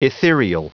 Prononciation du mot ethereal en anglais (fichier audio)
ethereal.wav